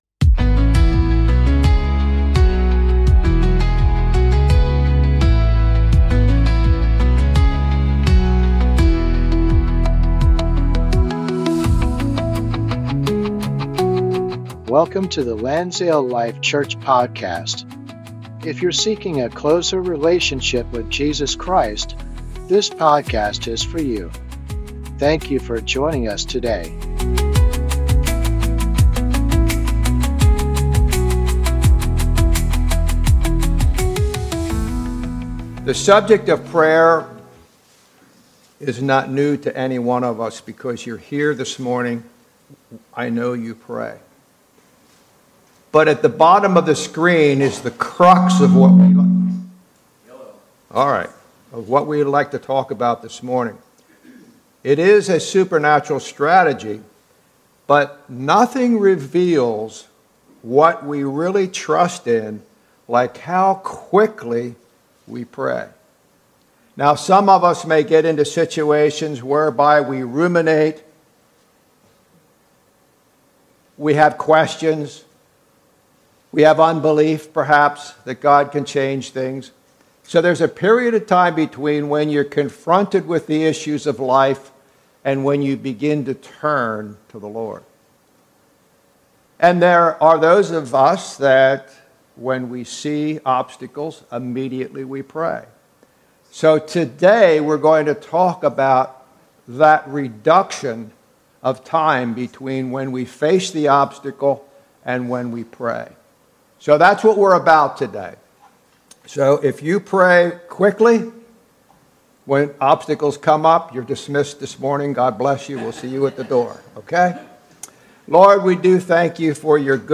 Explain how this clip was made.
Sunday Service - 2026-02-15